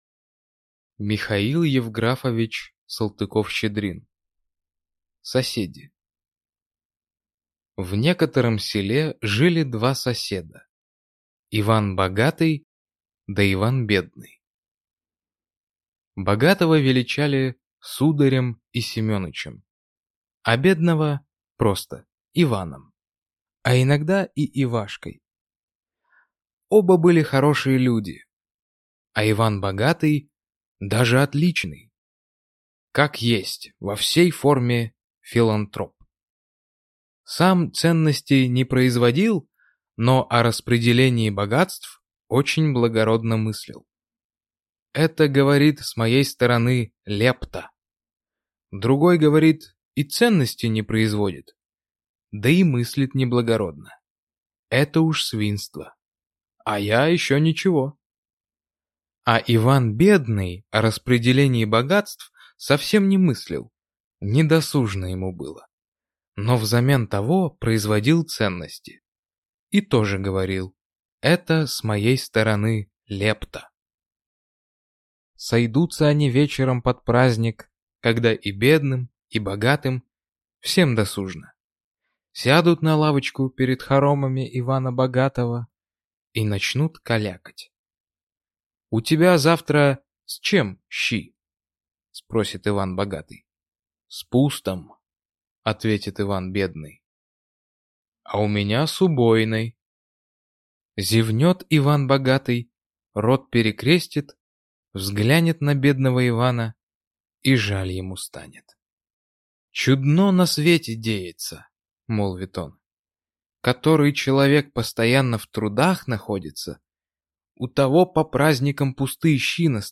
Аудиокнига Соседи | Библиотека аудиокниг